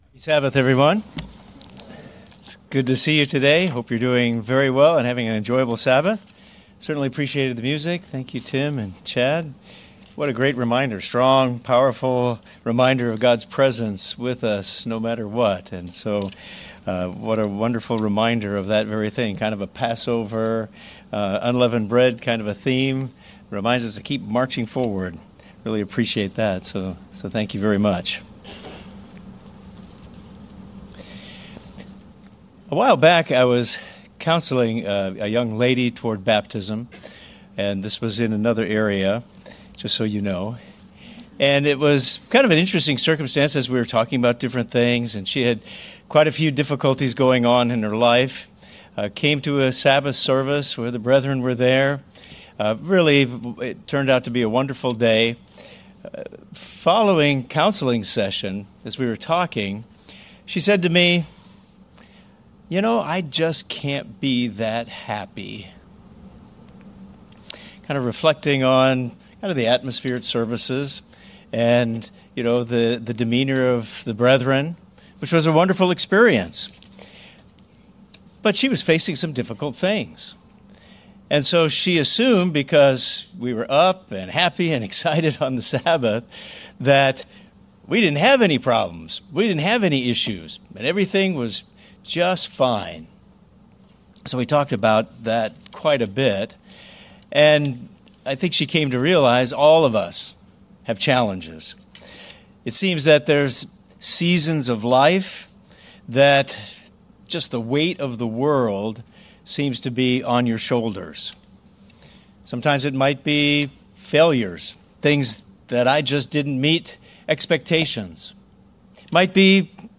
This message explores how God’s promises provide real, practical strength to find hope and courage, even in your hardest moments and strength to defeat discouragement.